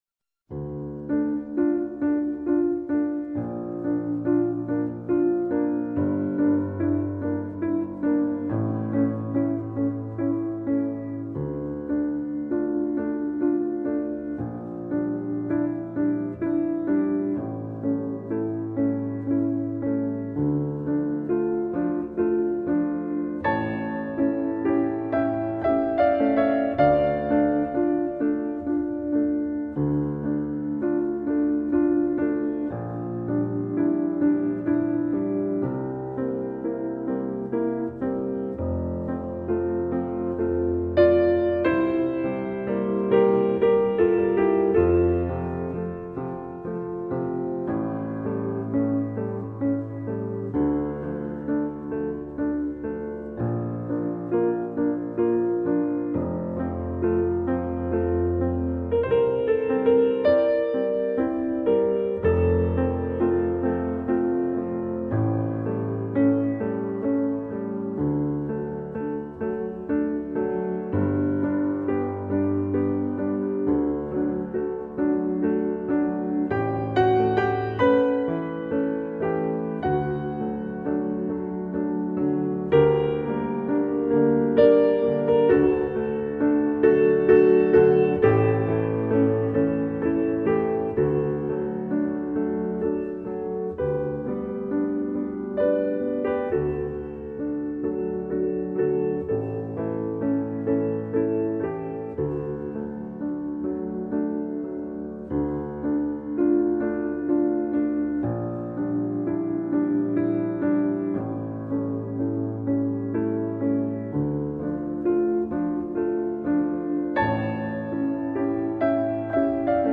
伴奏：